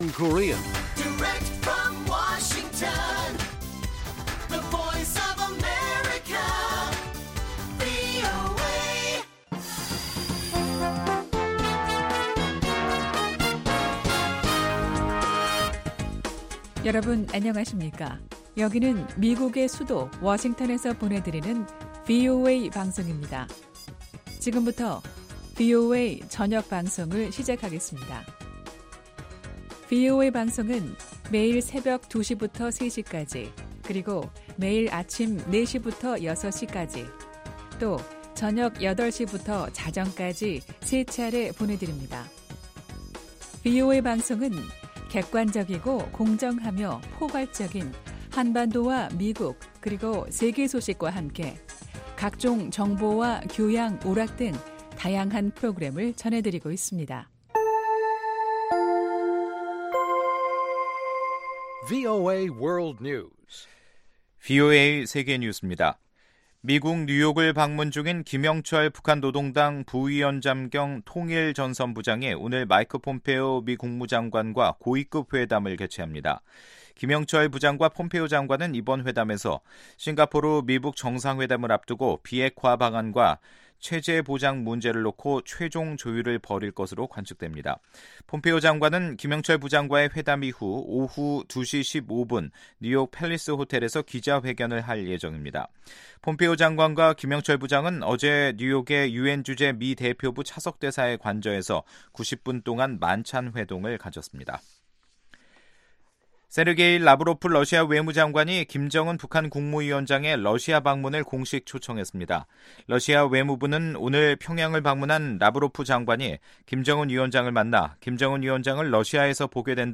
VOA 한국어 간판 뉴스 프로그램 '뉴스 투데이', 2018년 5월 31일1부 방송입니다. 미국의 목표는 한반도의 완전한 비핵화이며 이를 검증 가능한 방식으로 확인하는 것이라고 백악관이 밝혔습니다. 국무부는 미-북 정상회담 준비를 위해 두 나라 실무팀이 싱가포르에서 만났다고 확인했습니다.